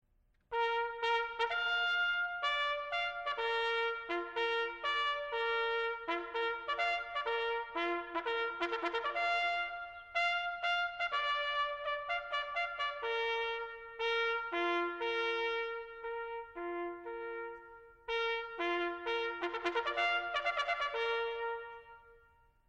The bugle call sounded at retreat was first used in the French Army and dates back to the crusades. When you hear it, you are listening to a beautiful melody that has come to symbolize the finest qualities of the soldiers of nearly 900 years.